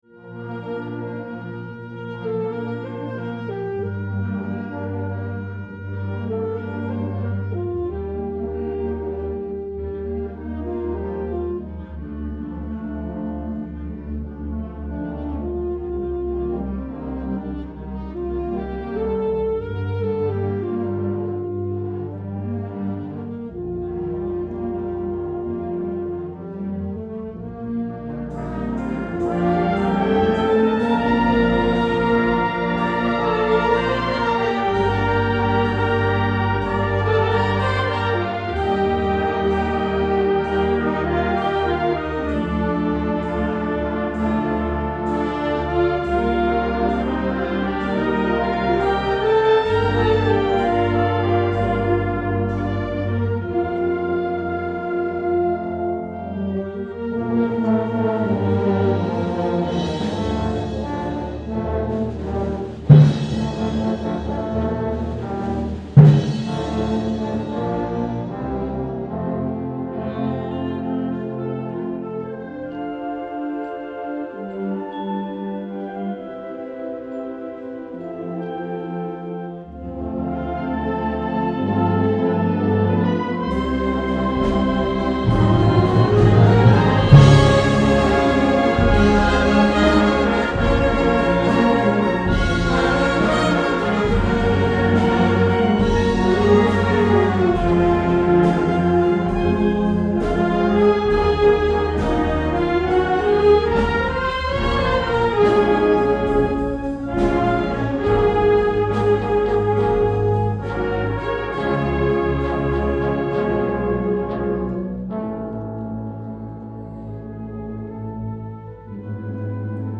2008 Winter Concert
December 14, 2008 - San Marcos High School